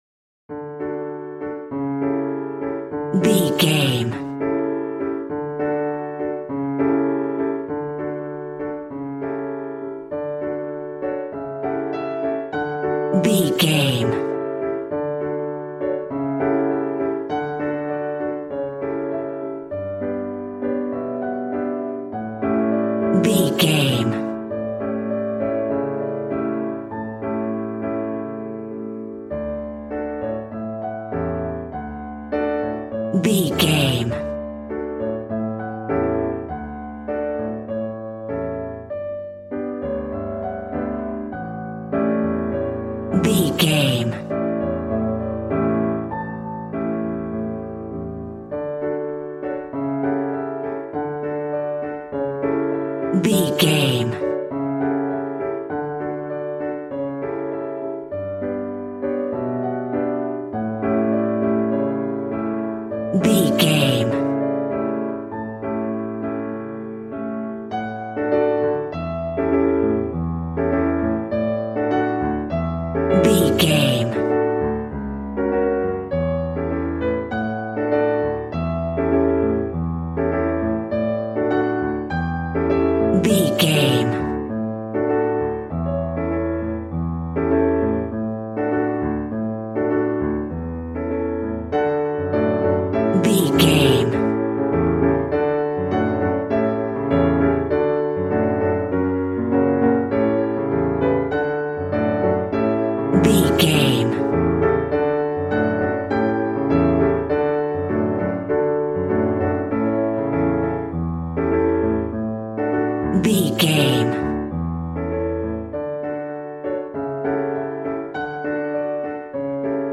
Ionian/Major
passionate
acoustic guitar